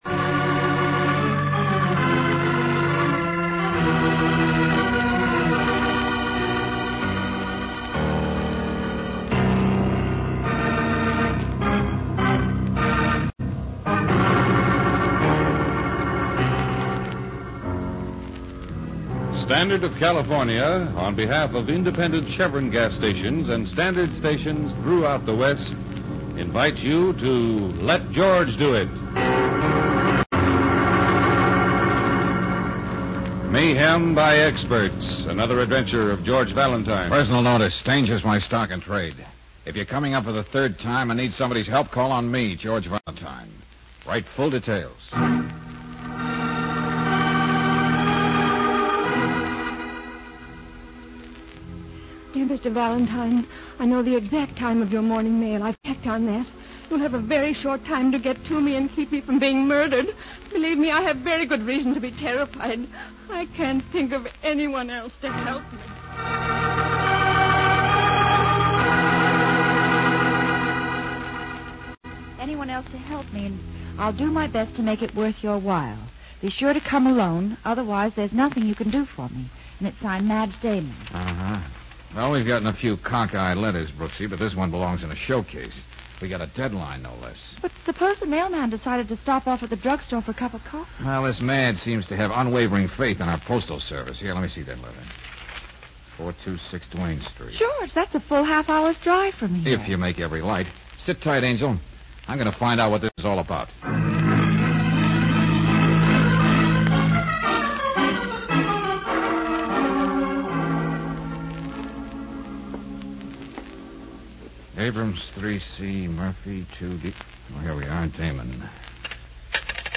Let George Do It Radio Program
starring Bob Bailey